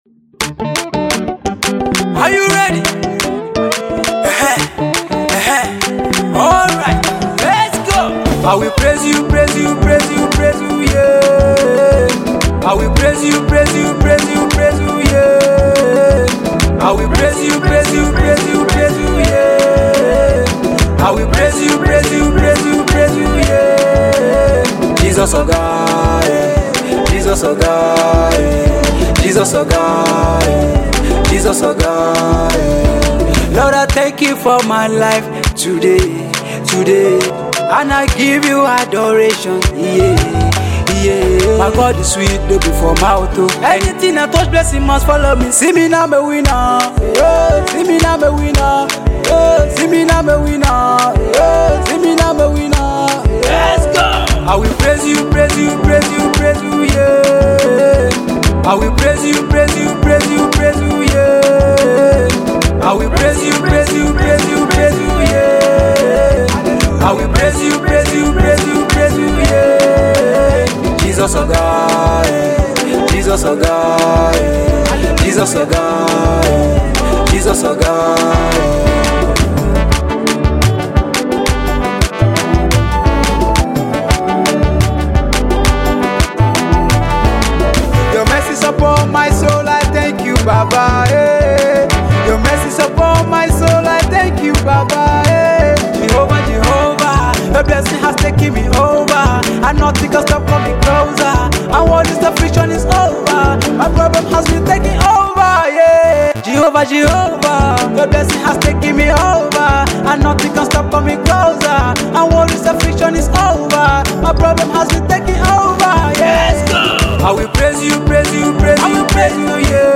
praise song